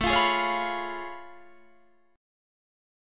Listen to A+M9 strummed